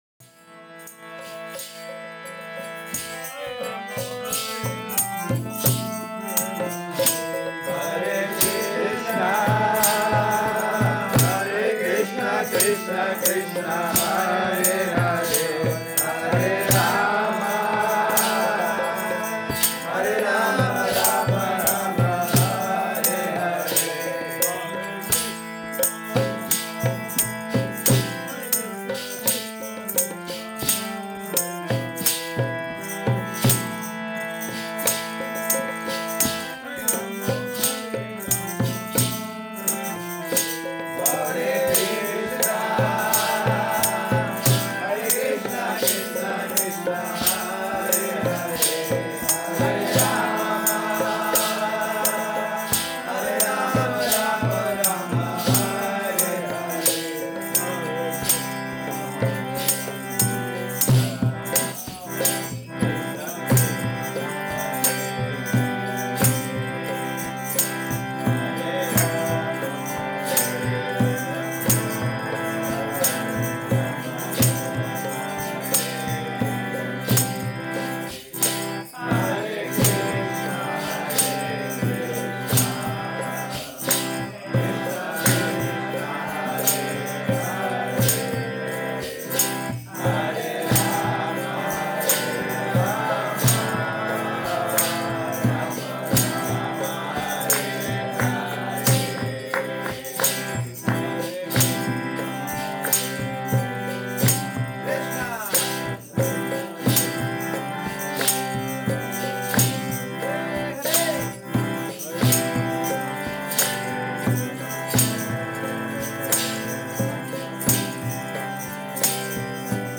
Přednáška BG-9.13-14 – Mantra párty klub ShenGen